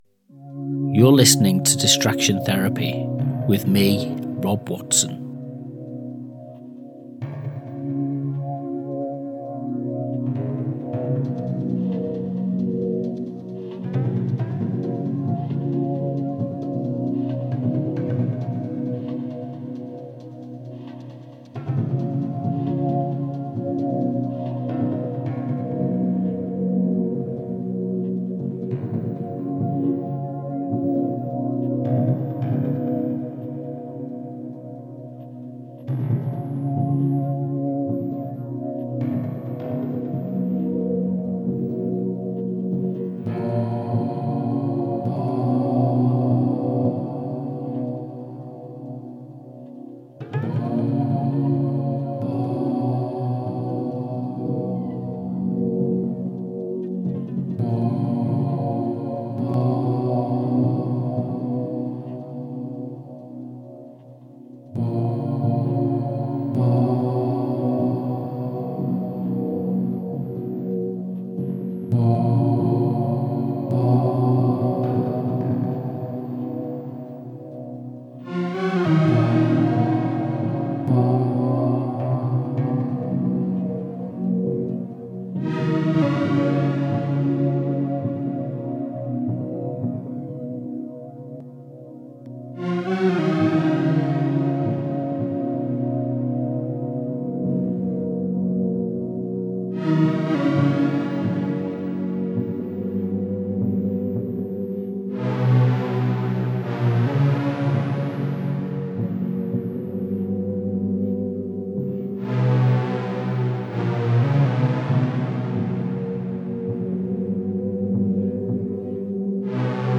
Each edition of Distraction Therapy opens a space—quiet, reflective, unhurried.
This latest mix, like those before it, offers a moment to turn our attention inward—not in retreat, but with care.